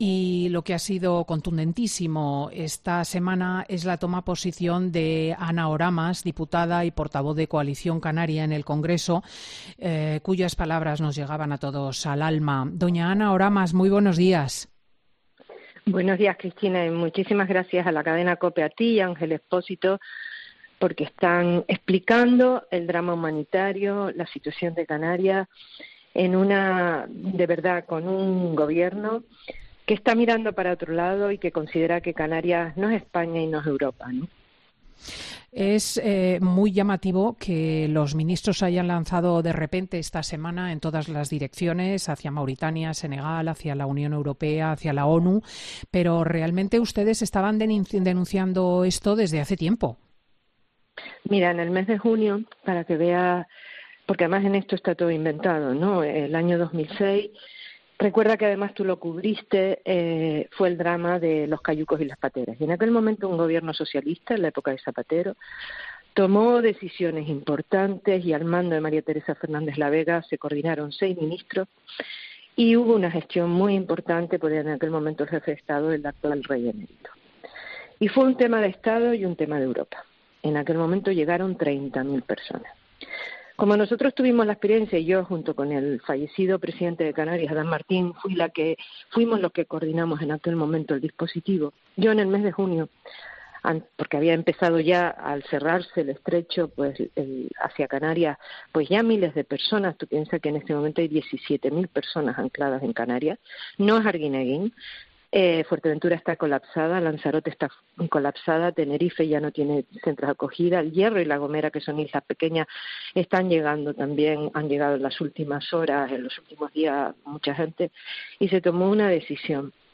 La diputada de Coalición Canaria ha estallado en COPE contra la decisión del Gobierno de no trasladar inmigrantes a la Península